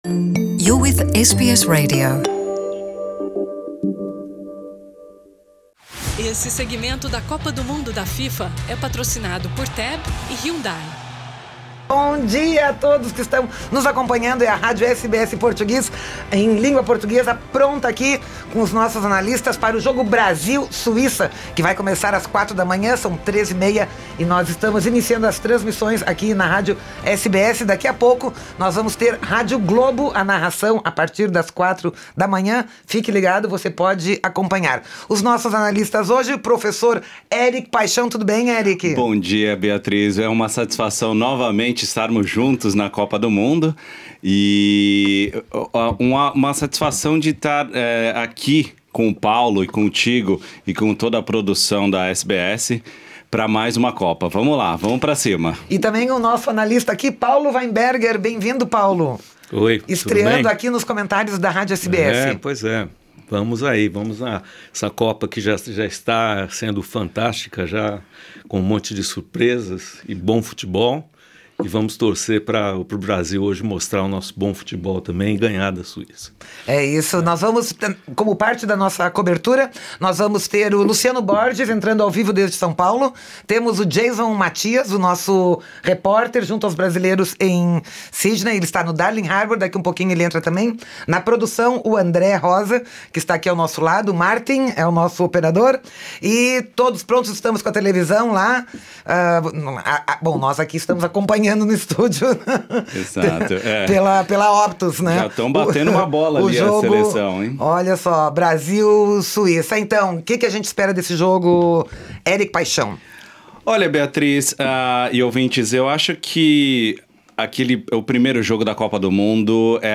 Brasil 1 X 0 Suíça: Radialista brasileiro emociona com narração do gol de Philippe Coutinho